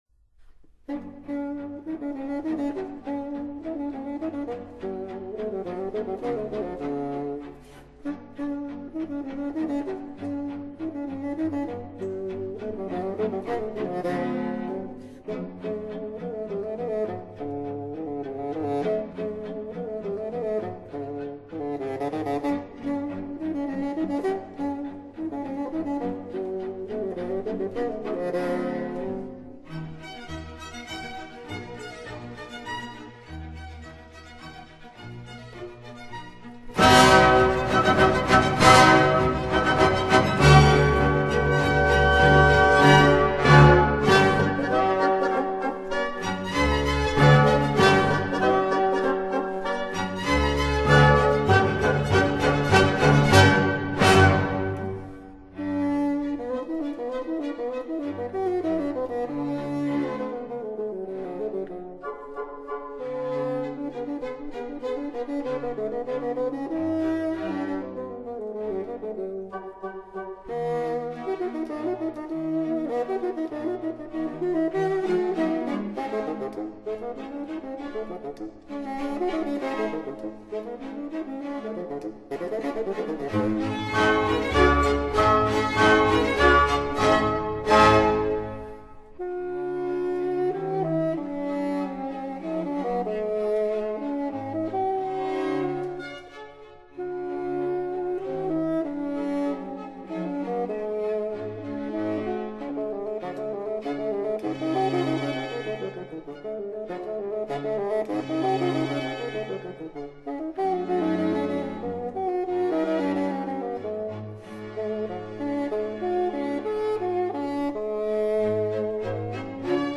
低音管（英語：Bassoon，義大利語：Fagotto） ，或稱巴松管，在中國大陸又稱大管。